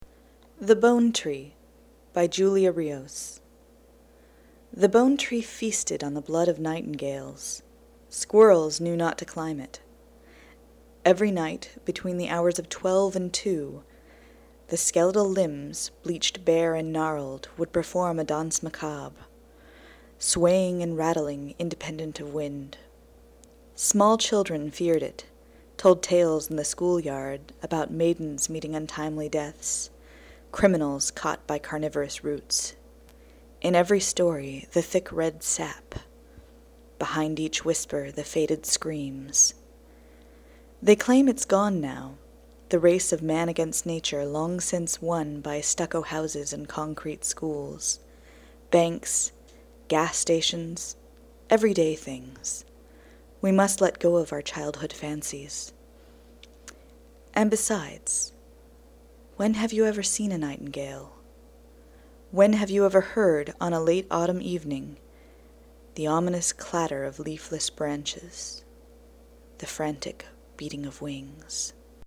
2008 Halloween Poetry Reading